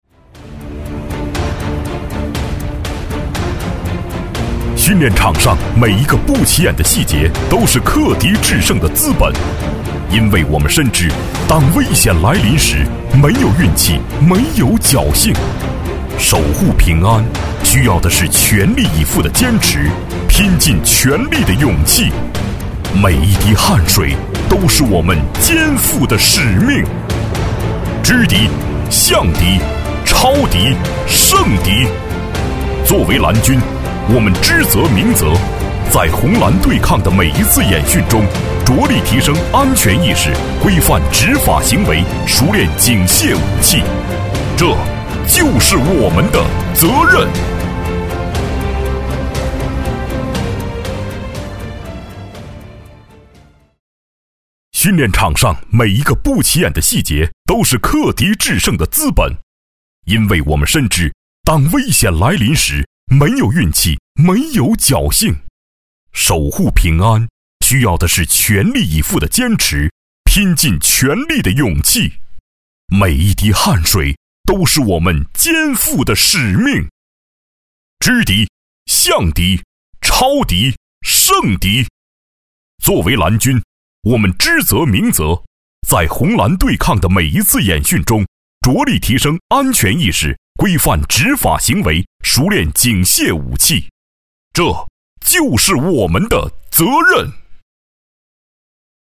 15 男国463_专题_政府_东莞公安蓝军样音_激情 男国463
男国463_专题_政府_东莞公安蓝军样音_激情.mp3